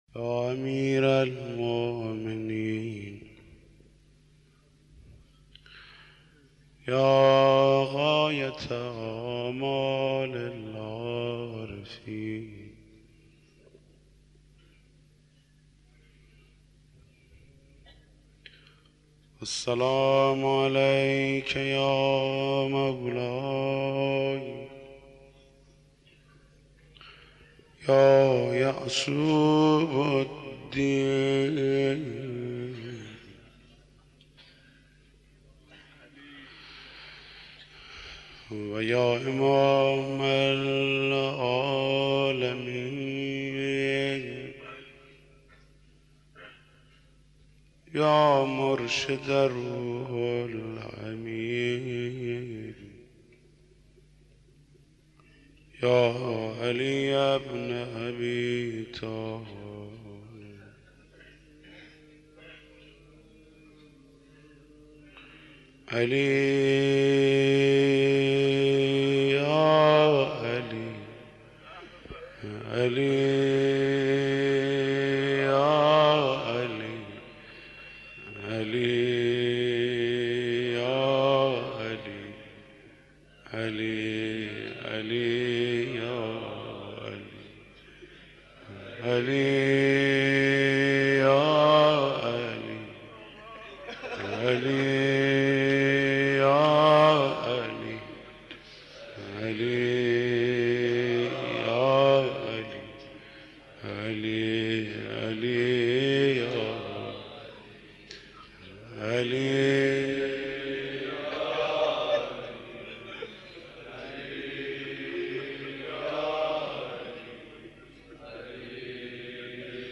محمود کریمی مداح
مناسبت : شب چهارم محرم